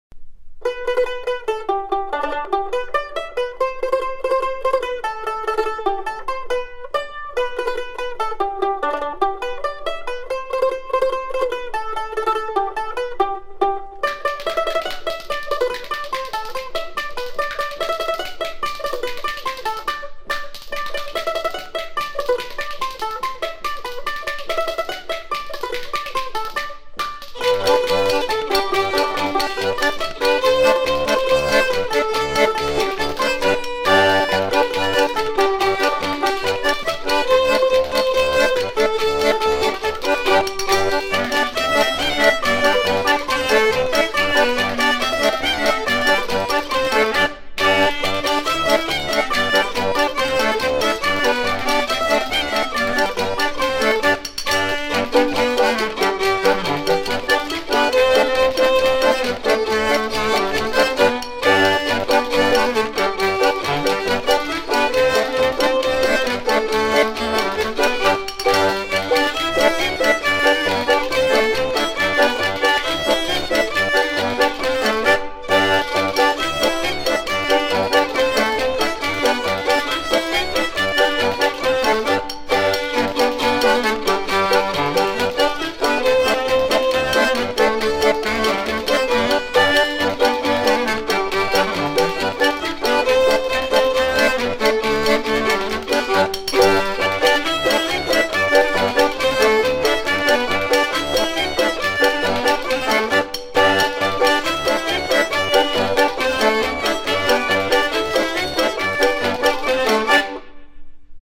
Polka
Air de polka connu dans tout l'Ouest de la France
danse : polka